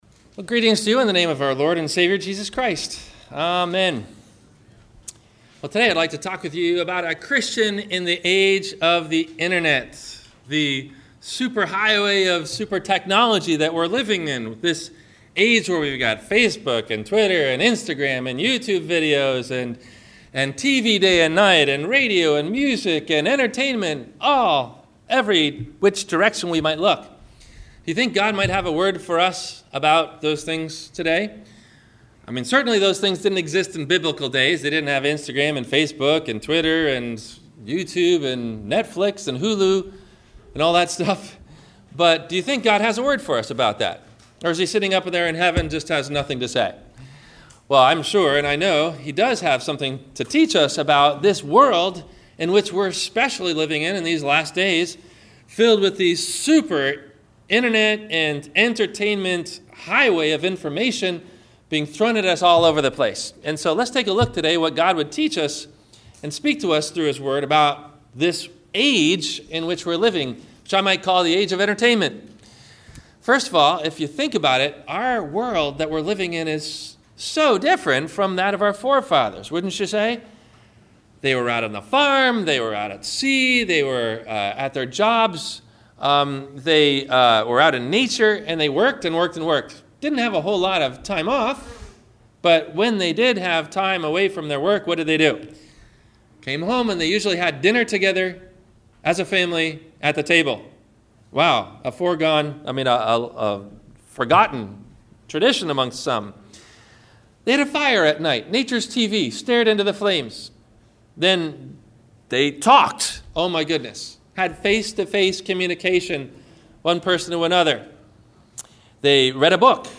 A Christian in the Age of The Internet - Sermon - August 14 2016 - Christ Lutheran Cape Canaveral